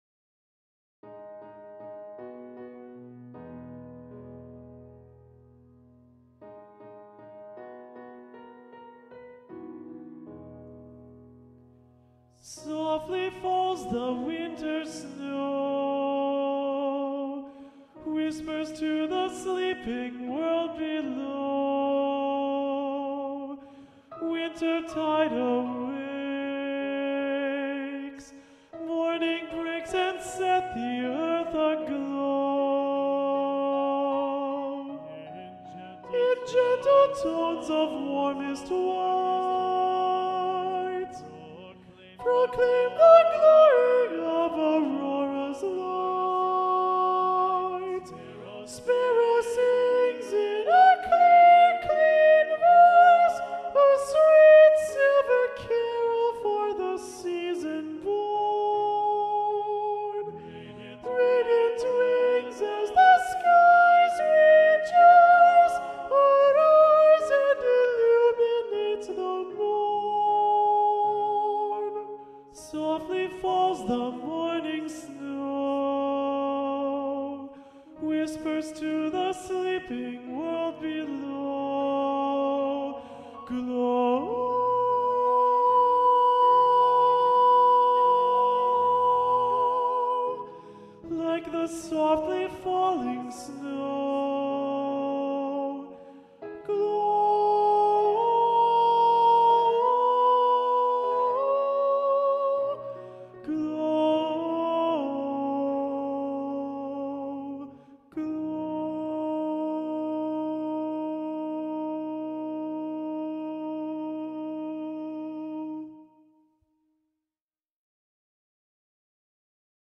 - Chant normalement à 4 voix mixtes SATB + piano
SATB Soprano Predominant